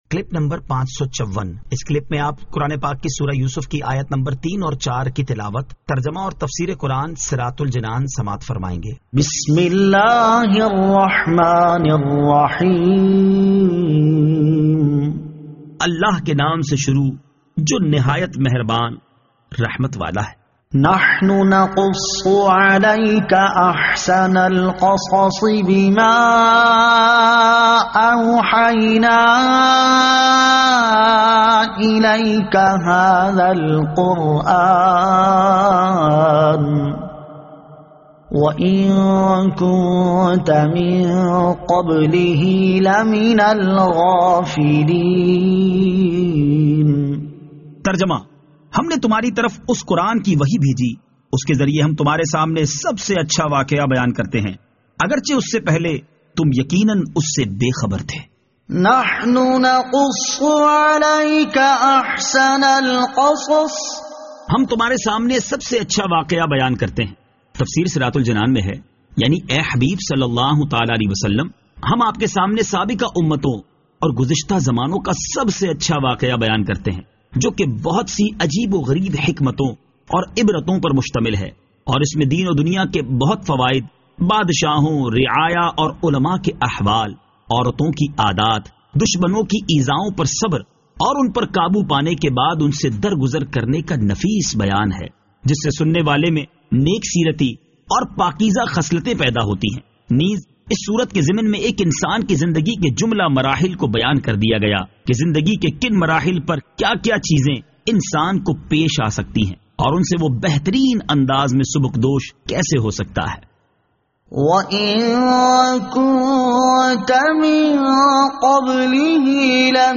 Surah Yusuf Ayat 03 To 04 Tilawat , Tarjama , Tafseer